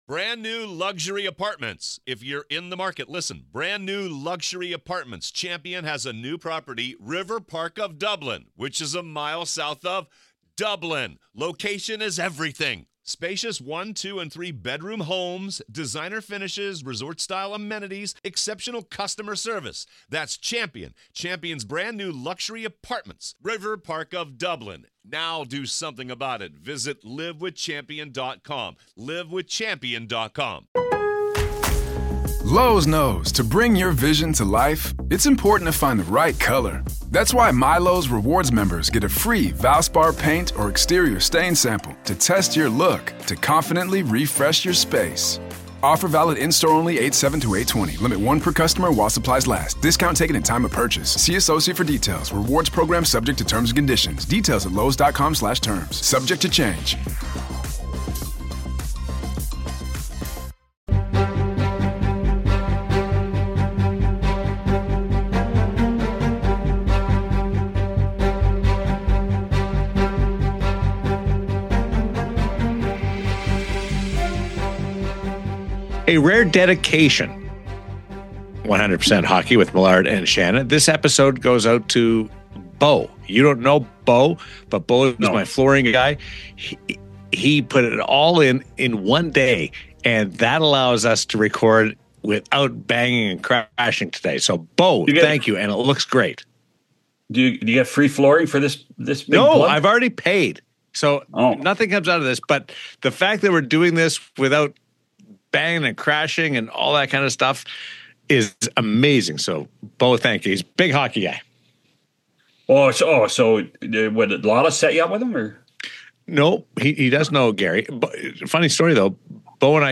wide-ranging conversation